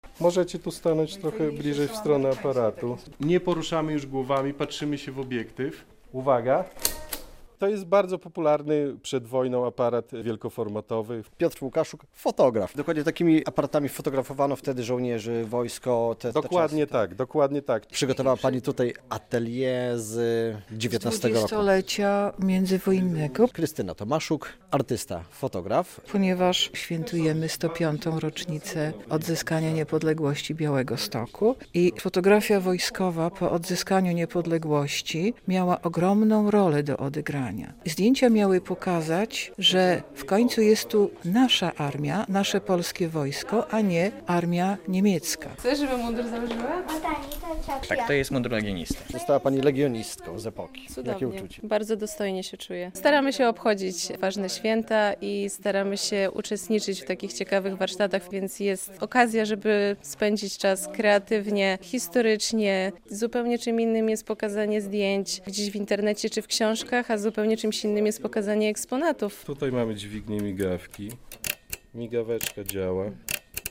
Muzeum Wojska upamiętniło 105. rocznicę odzyskania niepodległości przez Białystok